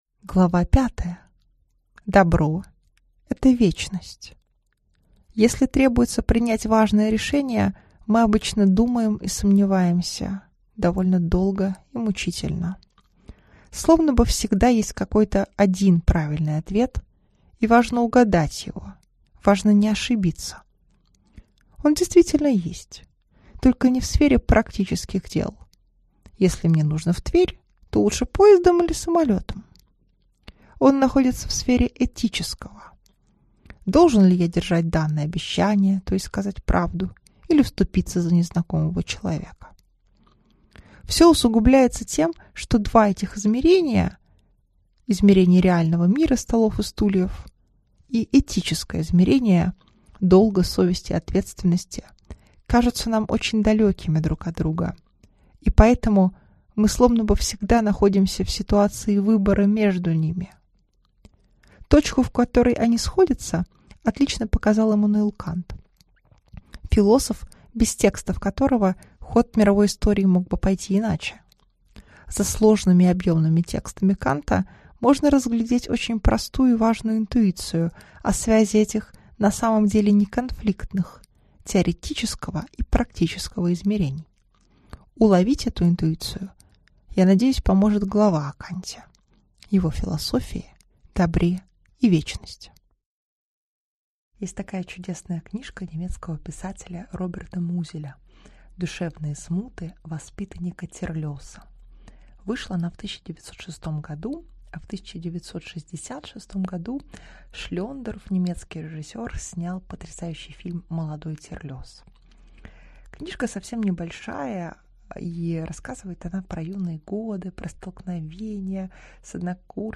Аудиокнига Добро – это вечность | Библиотека аудиокниг